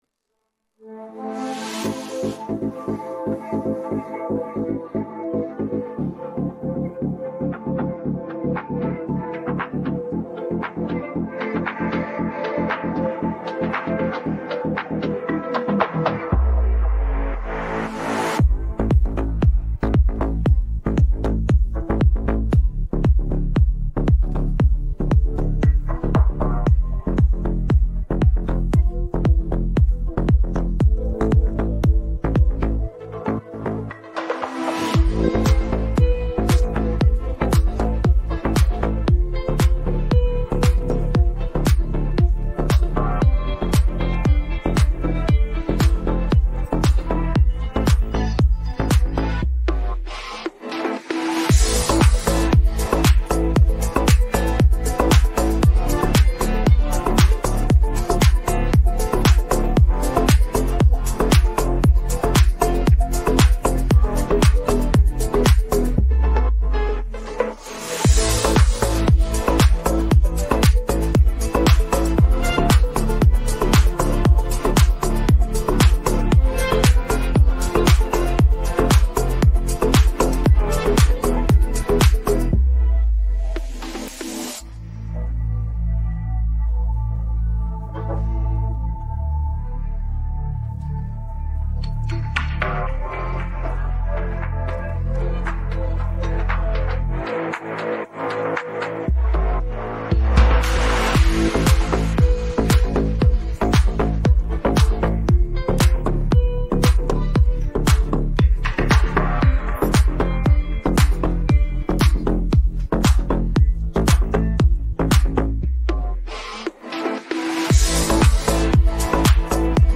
енергійний гімн, ідеальний для караоке онлайн!